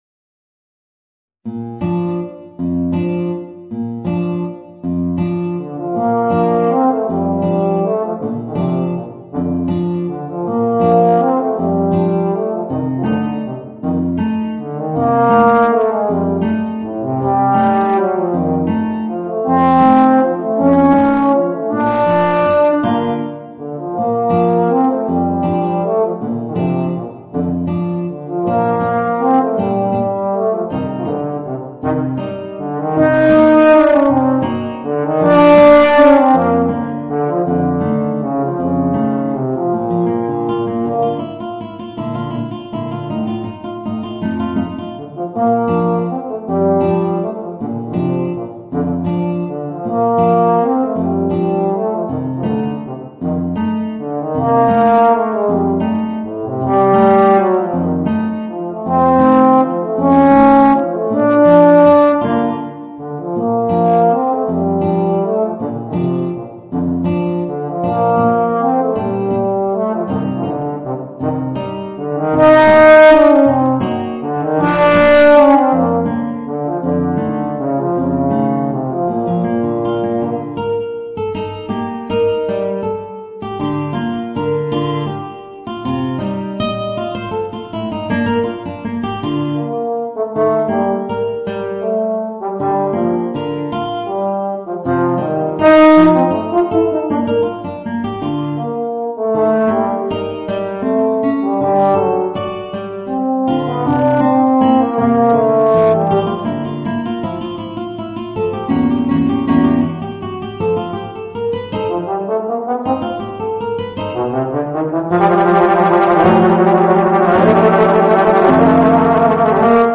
Voicing: Euphonium and Guitar